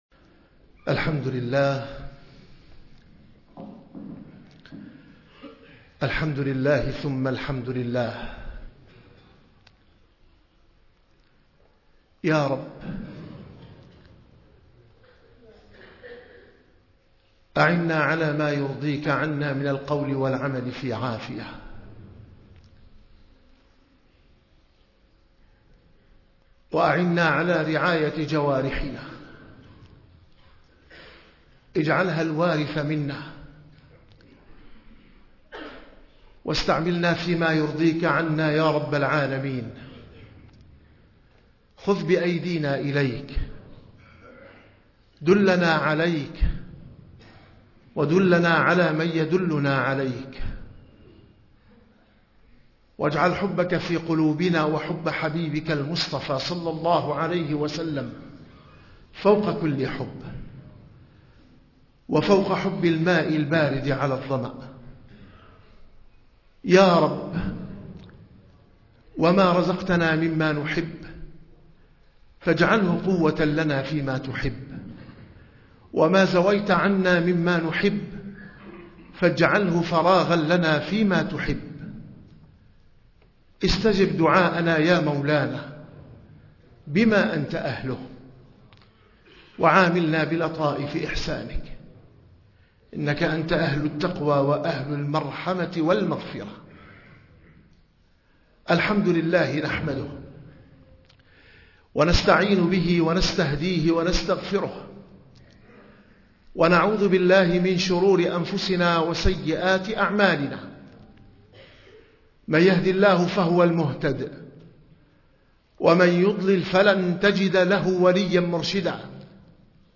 - الخطب - خطبة